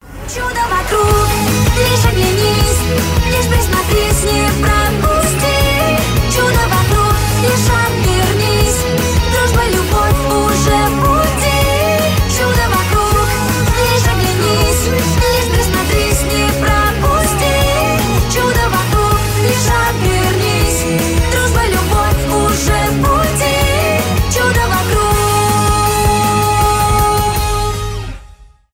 детские , из мультфильмов , ost , русские , cover